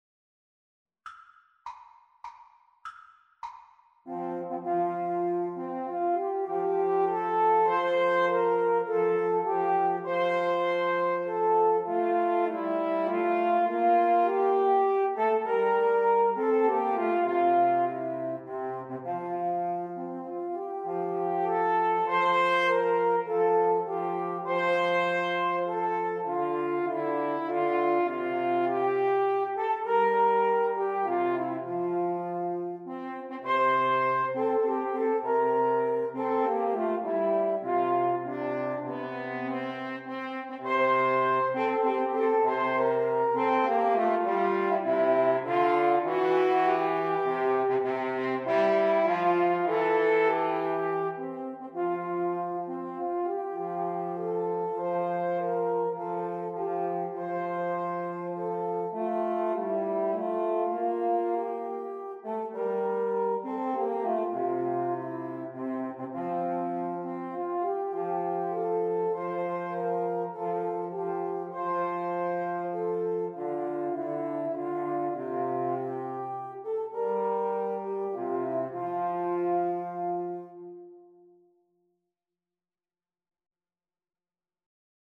French Horn 1French Horn 2French Horn 3
3/4 (View more 3/4 Music)